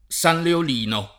San leol&no] o San Leonino [